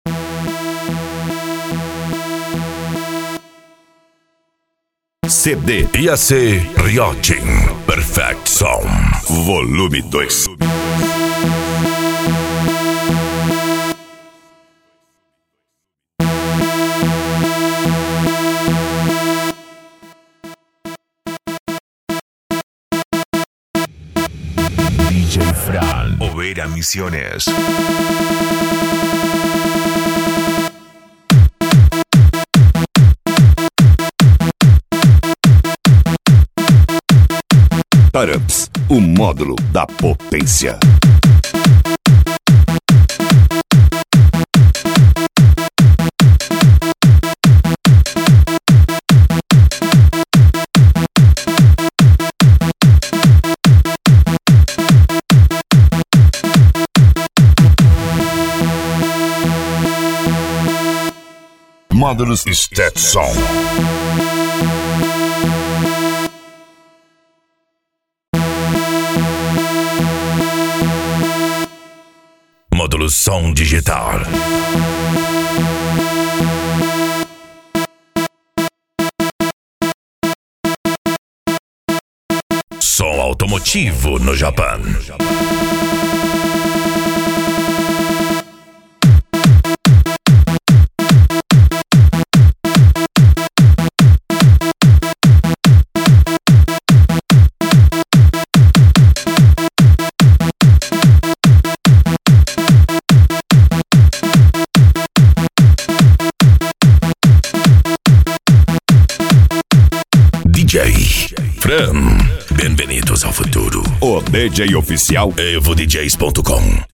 japan music
Variados